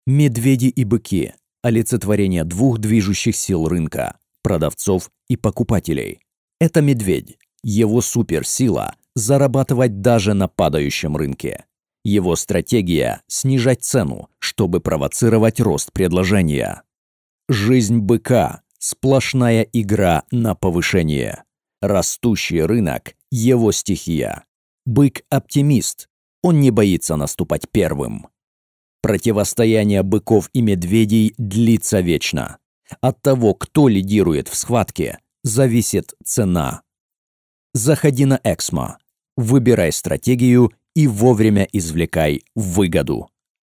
Native speaker Male 30-50 lat